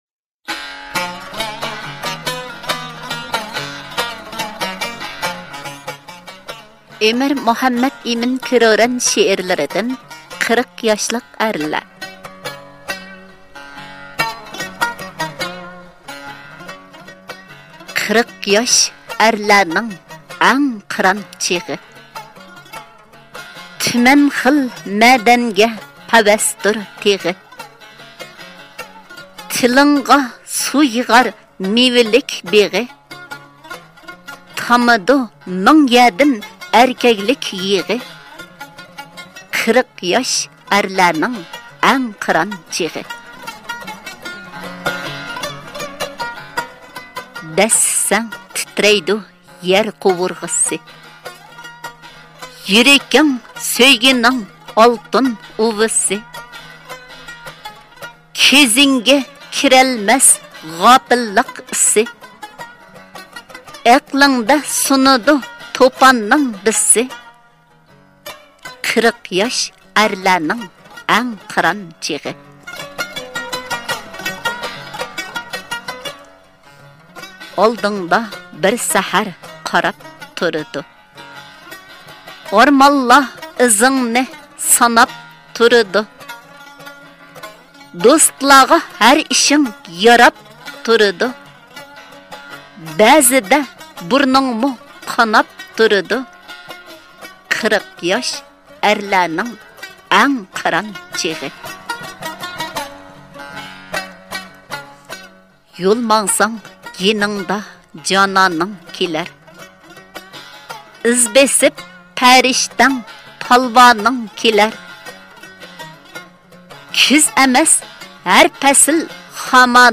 قىرىق ياشلىق ئەرلەر(ئاۋازلىق)